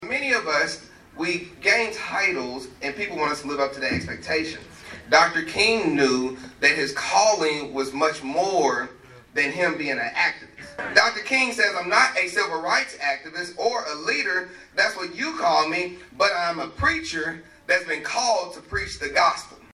Monday morning, the community gathered at HCC to honor Dr. Martin Luther King Jr.’s vision for justice and equality.